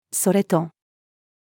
それと-female.mp3